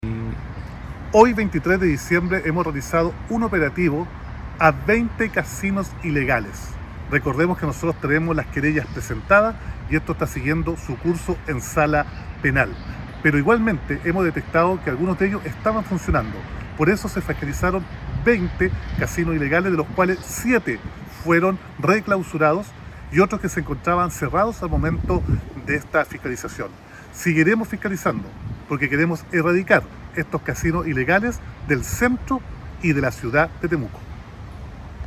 Roberto-Neira-alcalde-de-Temuco-2.mp3